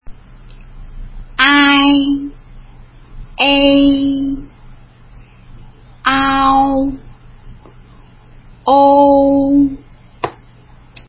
前の母音を強く、後の母音は軽く添えるように発音
ai ｢アィ｣と発音するイメージ。
ei ｢エィ｣と発音するイメージ。
ao ｢アォゥ｣と発音するイメージ。
ou ｢オゥ｣と発音するイメージ。